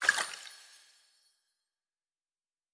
Potion and Alchemy 08.wav